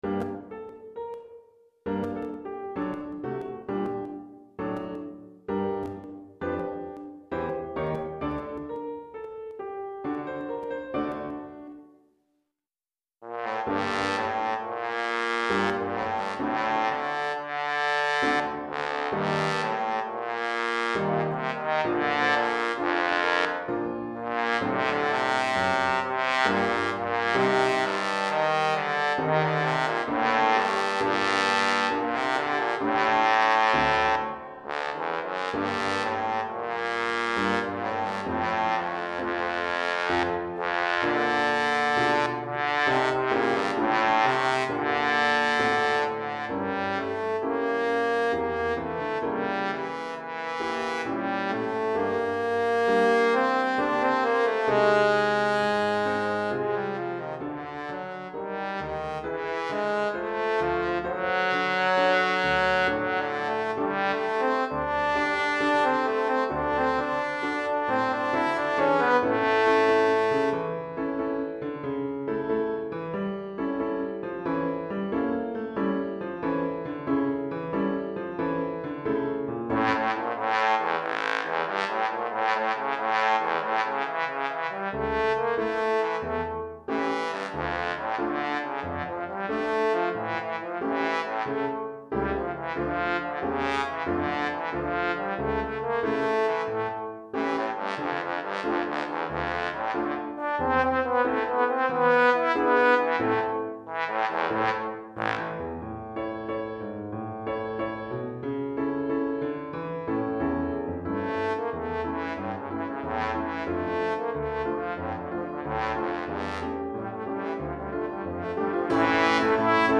Répertoire pour Trombone - Trombone Basse et Piano